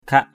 /kʱaʔ/ 1.